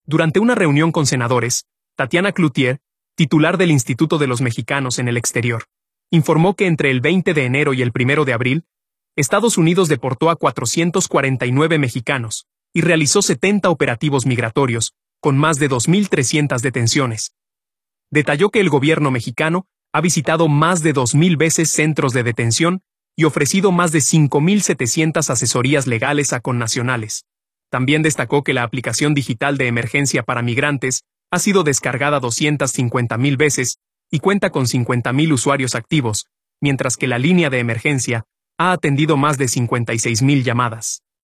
Durante una reunión con senadores, Tatiana Clouthier, titular del Instituto de los Mexicanos en el Exterior, informó que entre el 20 de enero y el 1 de abril, Estados Unidos deportó a 449 mexicanos y realizó 70 operativos migratorios con más de […]